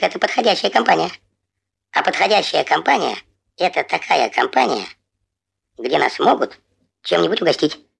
• Качество: высокое
Здесь вы найдете знаменитые песни, смешные диалоги и фразы персонажей в отличном качестве.